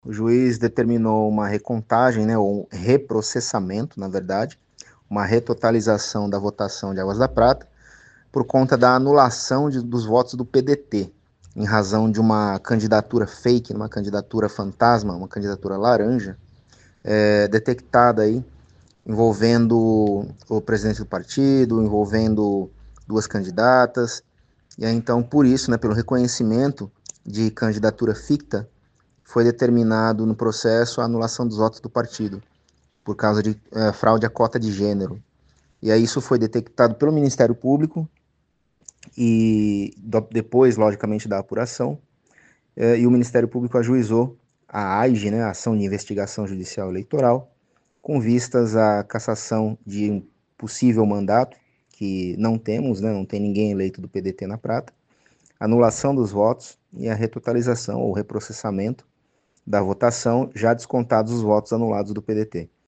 concedeu uma entrevista exclusiva à 92FM São João